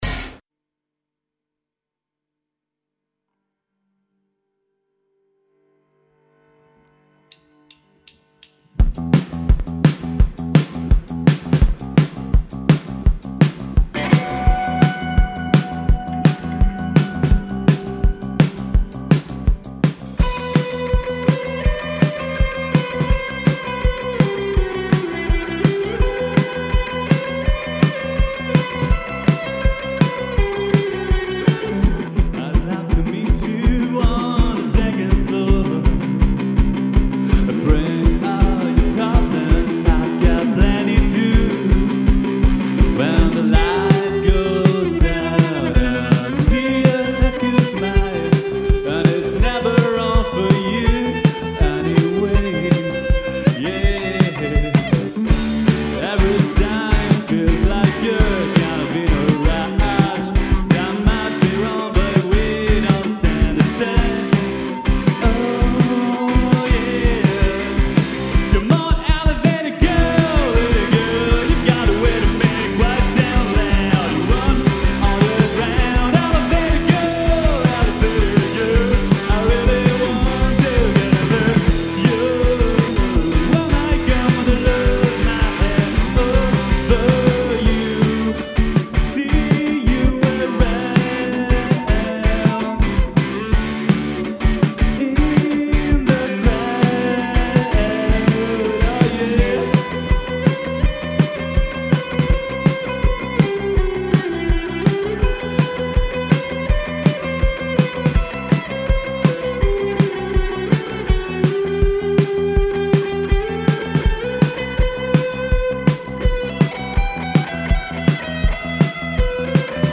Ogni giovedì alle 21.30, l’auditorium Demetrio Stratos di Radio Popolare ospita concerti, presentazioni di libri, reading e serate speciali aperte al pubblico.